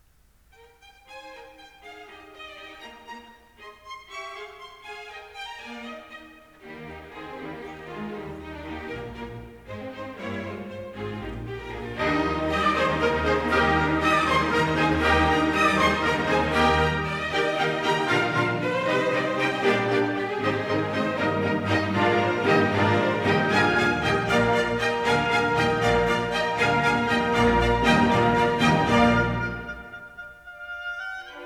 Adagio - Allegro con spirito